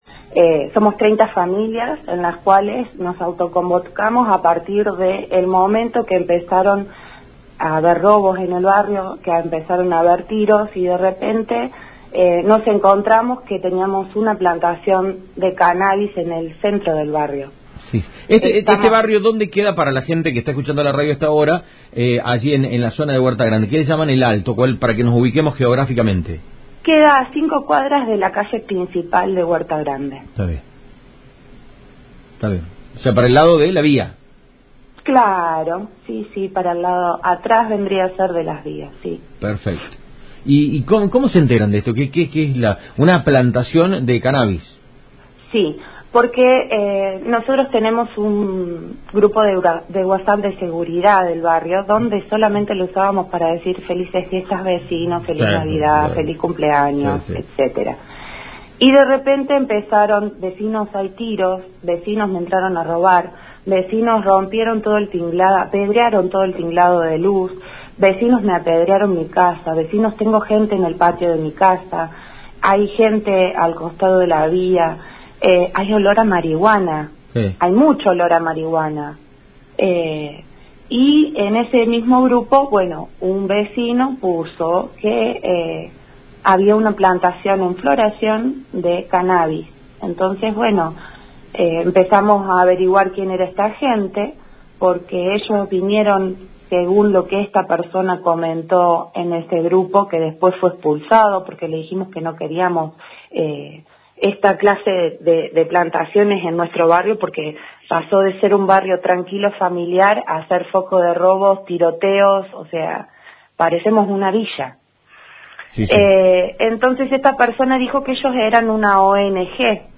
Una vecina del barrio “El alto” manifestó a Cadena Centro FM la situación y la preocupación con la que viven 30 familias.
VECINA-DE-HUERTA-GRANDE-_01.mp3